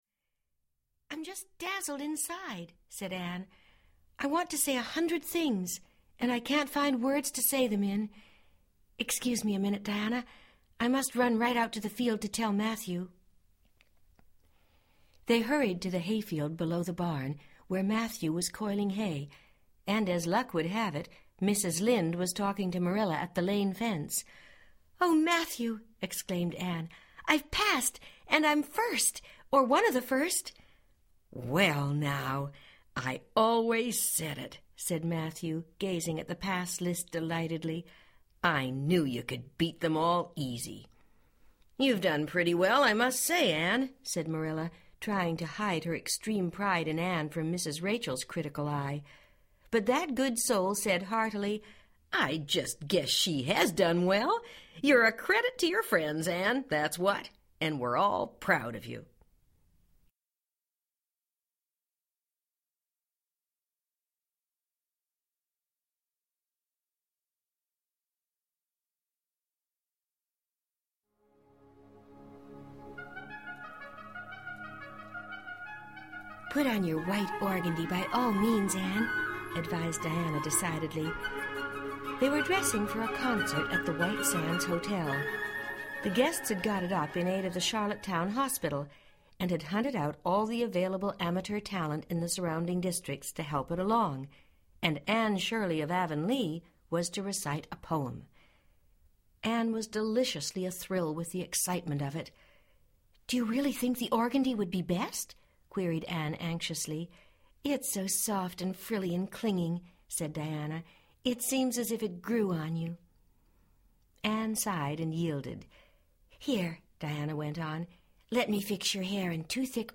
Anne of Green Gables Audiobook
Narrator